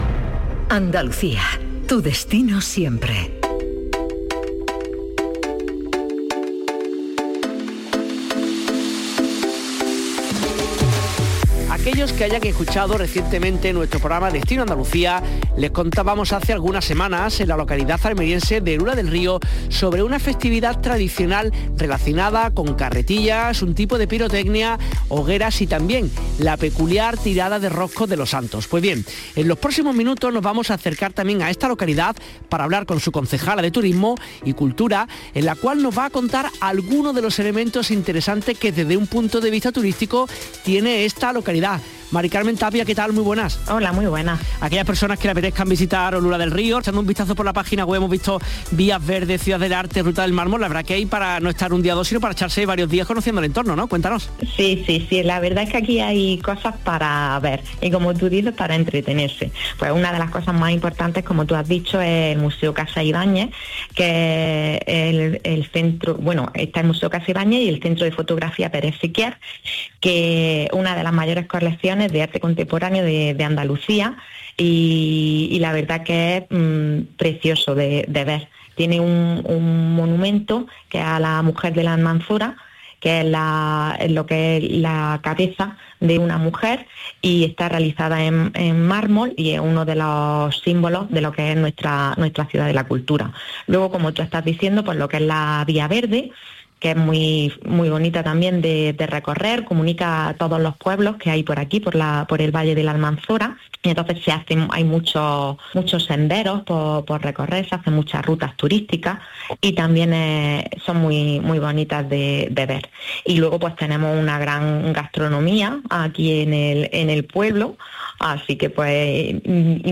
Corte del programa dedicado a Olula del Río: